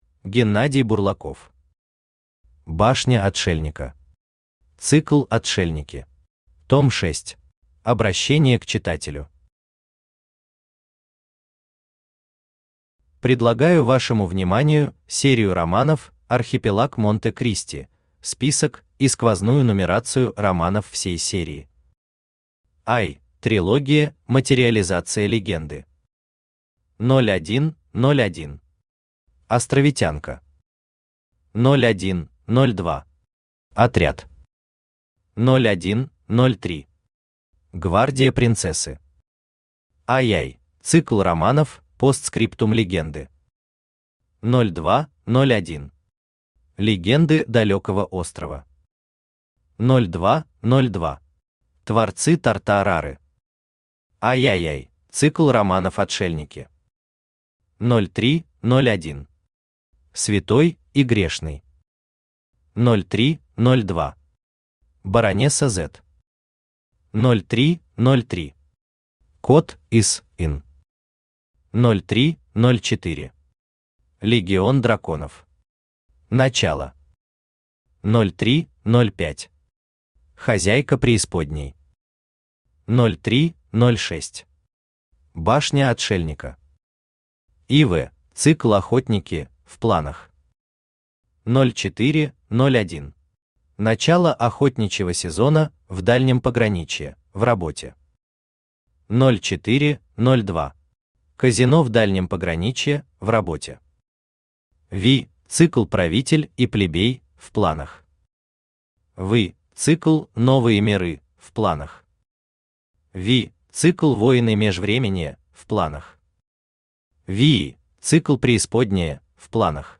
Аудиокнига Башня отшельника. Цикл «Отшельники». Том 6 | Библиотека аудиокниг
Том 6 Автор Геннадий Анатольевич Бурлаков Читает аудиокнигу Авточтец ЛитРес.